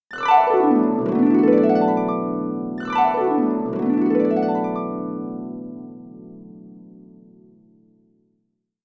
harp music
harp.mp3